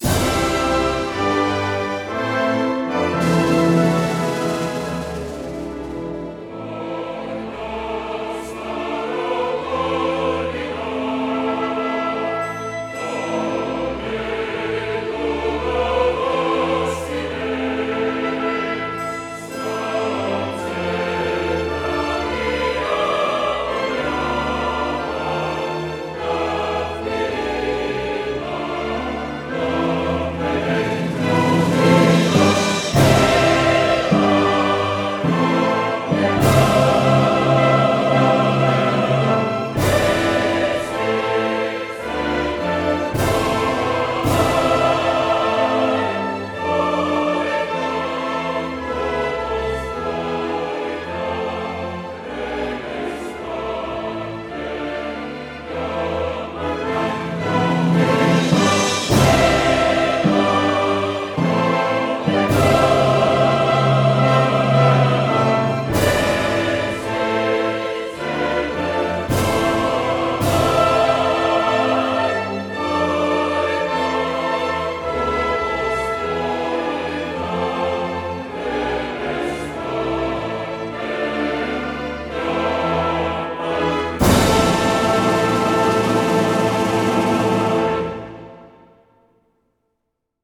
Маестро Найден Тодоров и Софийската филхармония направиха нови дигитални записи на двата химна в края на април.
Новите записи са направени в зала „България“ и освен че притежават висока художествена стойност, отговарят и на технически изисквания на новото време.
Инструментал - България